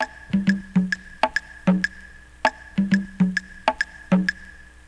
Track 47 (SV Tour) Bongo Loop.wav